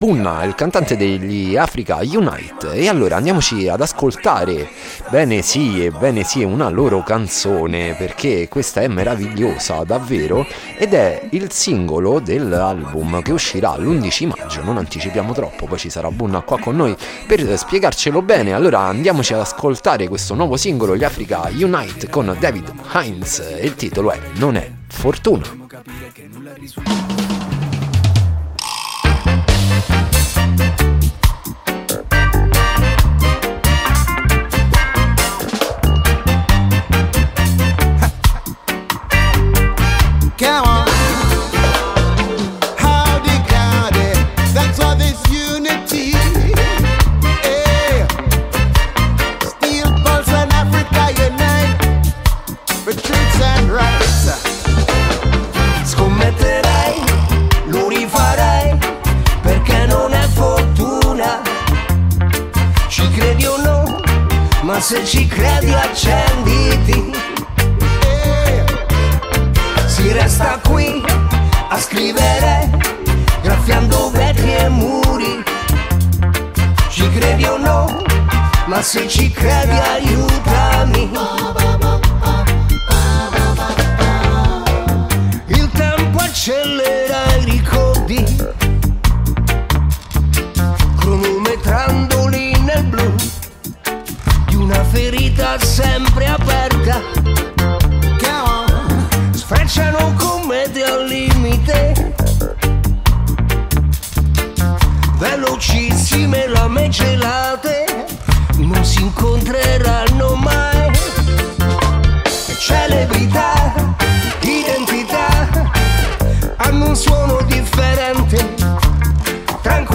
Artista-a-la-Vista-Intervista-Bunna-degli-Africa-Unite.mp3